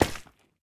resin_fall.ogg